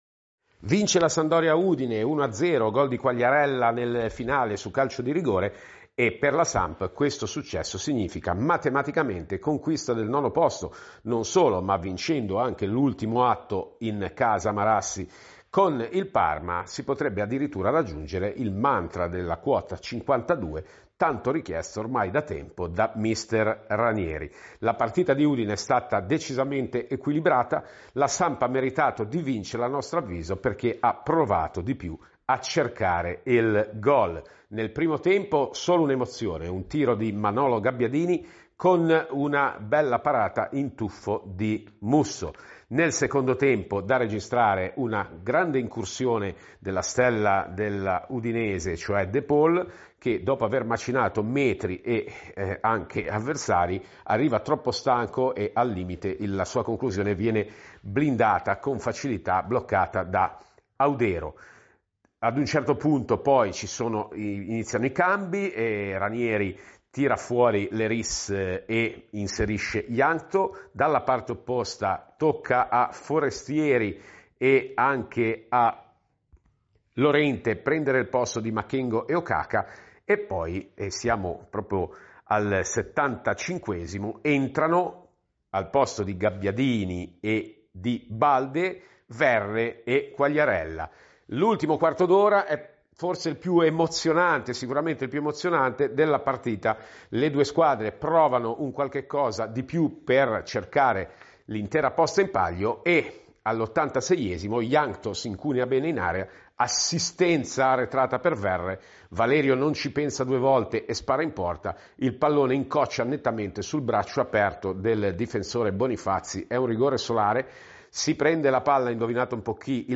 Il commento del Giornalista